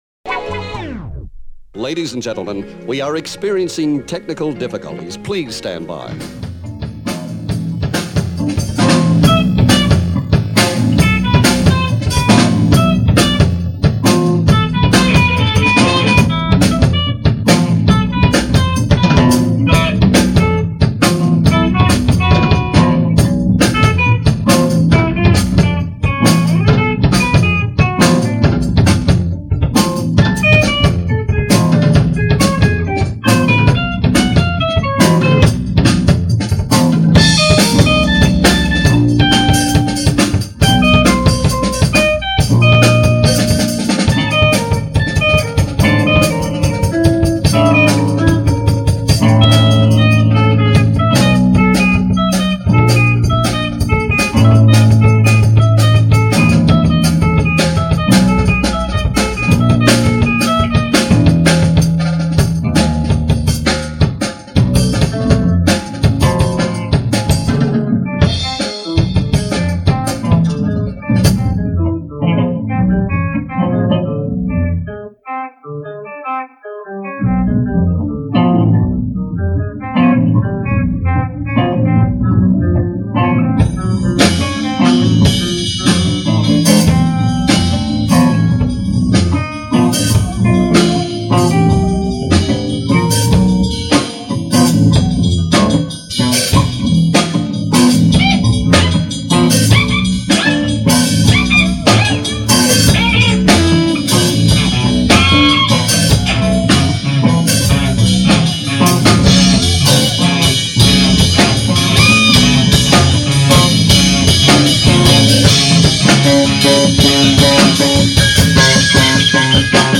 livejam - November 2005